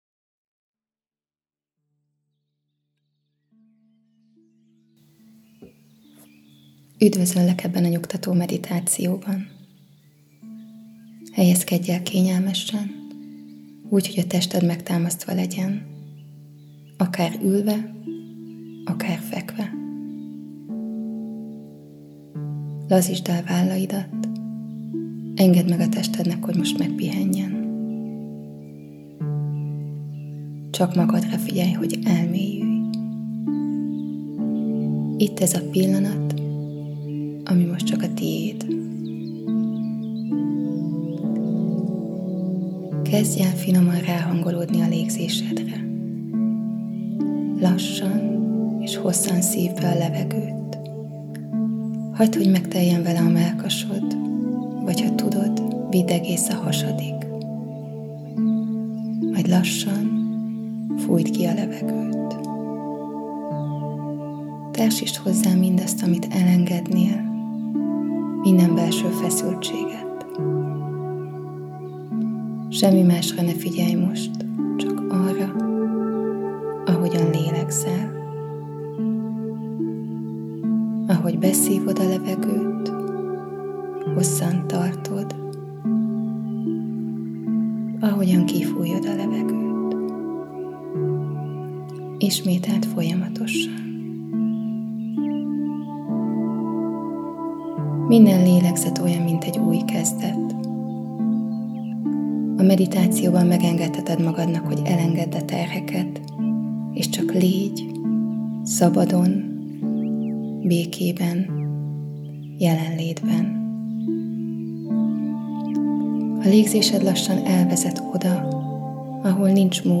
EGY MEDITÁCIÓ
Meditacio_1.mp3